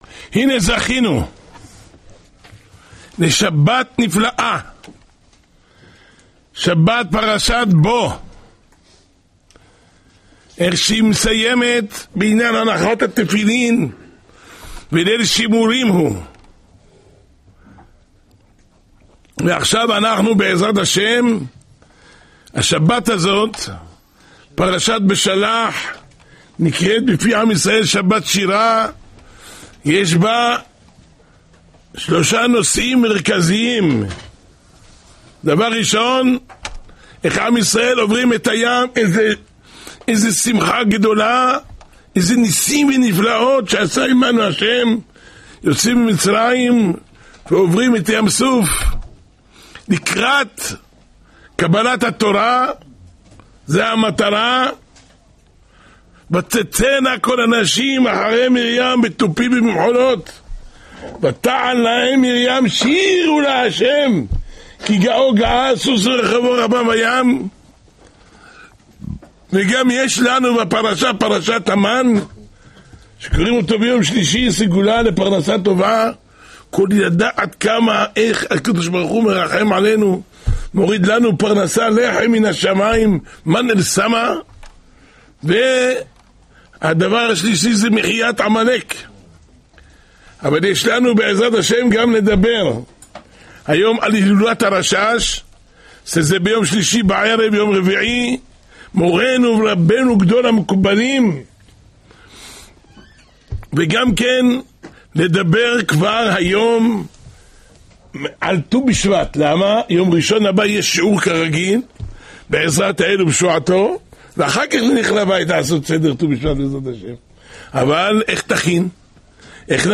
השיעור השבועי